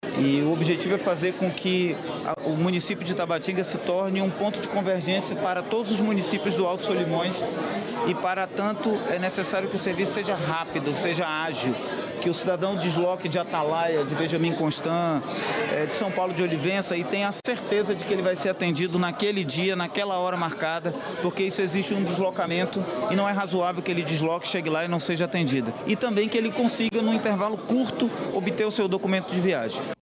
A modernização do posto faz parte de um projeto da Polícia Federal, para ampliar e melhorar o atendimento de quem busca emitir o passaporte. Além desta unidade no Aeroporto, o serviço também continua sendo ofertado no Shopping Studio 5, no Distrito Industrial, e em breve no bairro Parque 10, e em Tabatinga, no interior do Amazonas, como explica o superintendente da Policia Federal, Umberto Ramos.